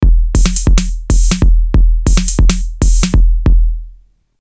• Channel #10: GM Drums ("Analog Kit")
Press play (not on tape) to get an impression about the nice bass/snare drum rhythm that you just have created with a single track.